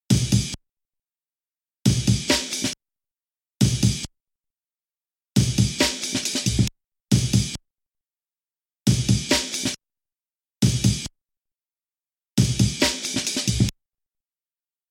Das zweite Beispiel nutzt die Option finish, um im ersten Durchgang nur ein Viertel des Breaks abzuspielen, beim zweiten Durchgang die Hälfte, beim dritten wieder ein Viertel, schießlich beim vierten Durchgang ein Dreiviertel. Dann geht es wieder von vorne los.